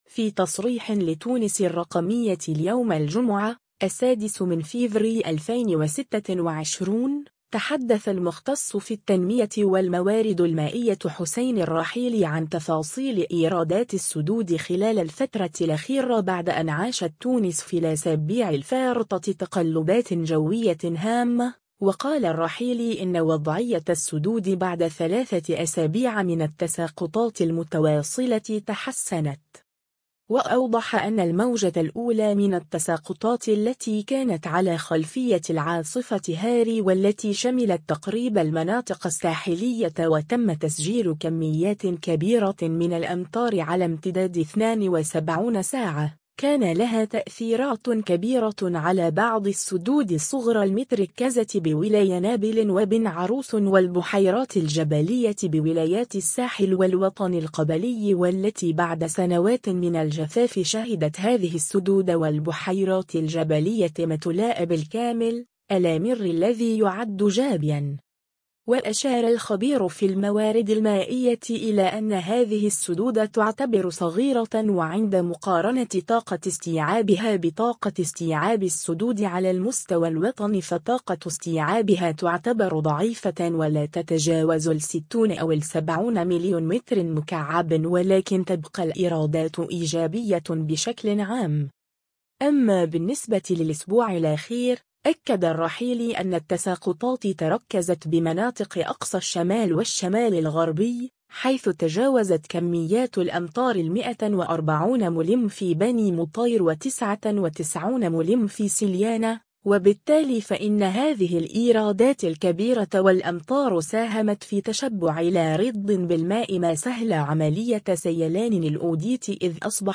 في تصريح لتونس الرّقمية اليوم الجمعة،